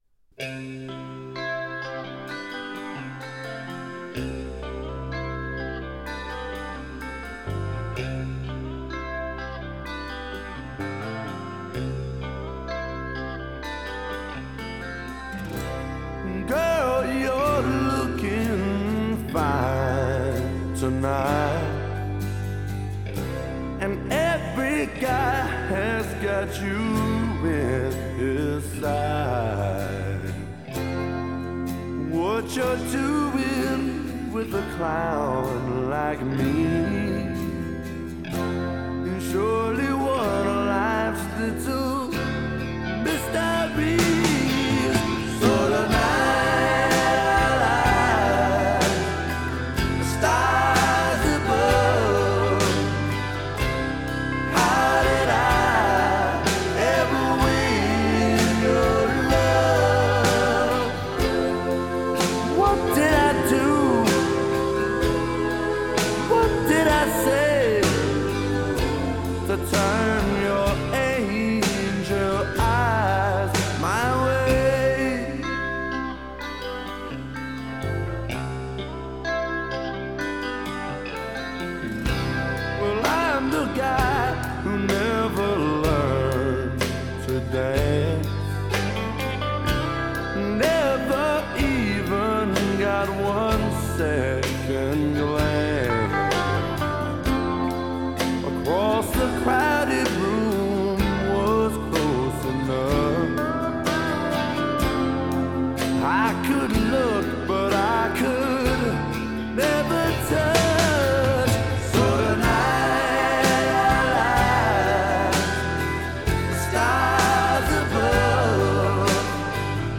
it's a heartbreaker